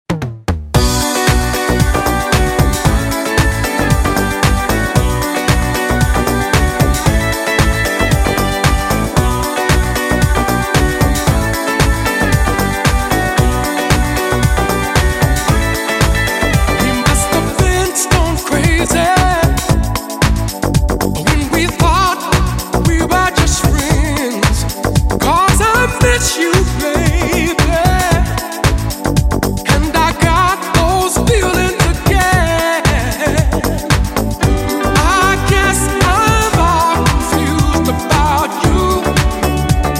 танцевальные , dance pop
ретро